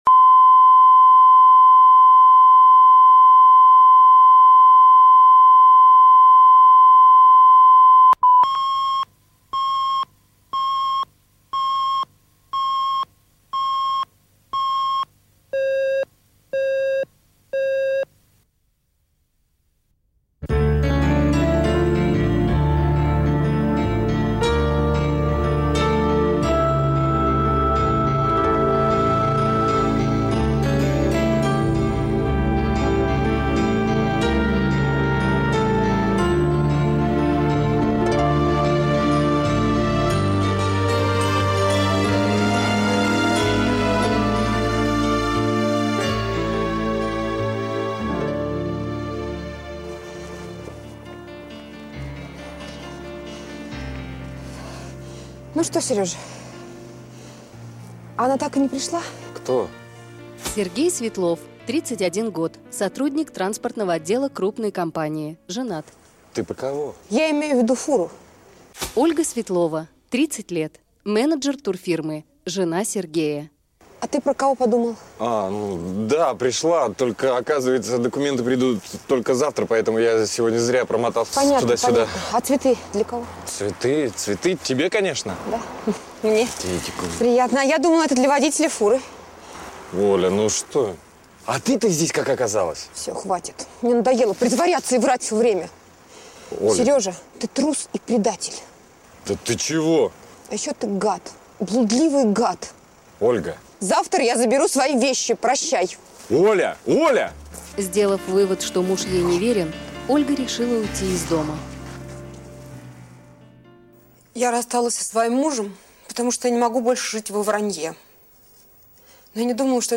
Aудиокнига Браки заключаются на небесах Автор Александр Левин.